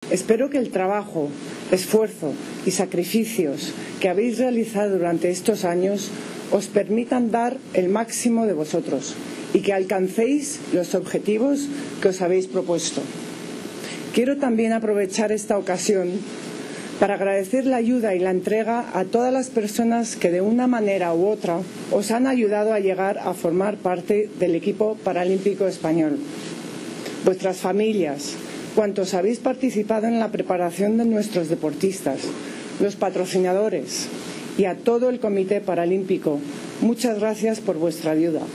Autoridades y miembros del equipo paralímpico posan en la despedida ante la sede del Conejo Superior de DeportesSAR la infanta doña Elena y la vicepresidenta del Gobierno en funciones, Soraya Sáenz de Santamaría, presidieron en Madrid el multitudinario acto de despedida del Equipo Paralímpico Español que viaja a Río de Janeiro para participar en los Juegos Paralímpicos, que se celebran entre el 7 y el 18 de septiembre.
En su intervención, doña Elena, que es presidenta de Honor del Comité Paralímpico Español (CPE) y estará presente en Río de Janeiro, agradeció la ayuda y entrega a todas las personas y empresas que han ayudado a nuestros deportistas en estos años de preparación, y animó a nuestros campeones a